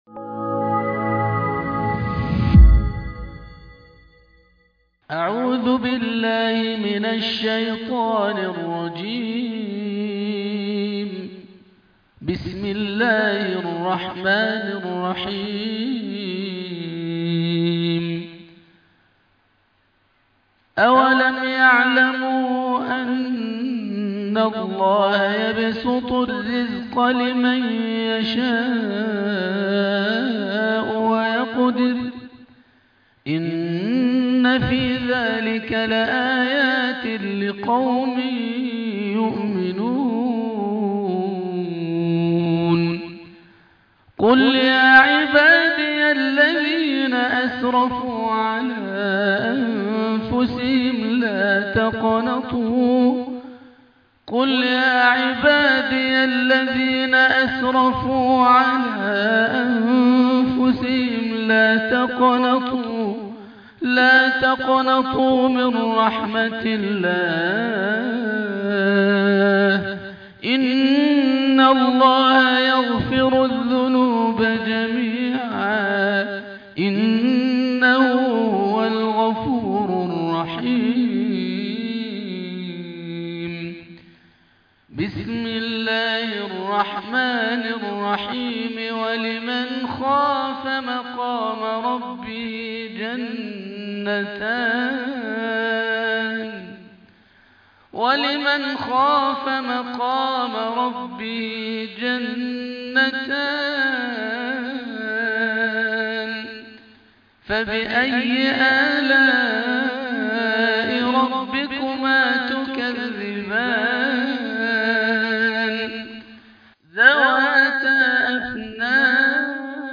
عنوان المادة تلاوة خاشعة ندية تأخذ بك إلى آفاق السماء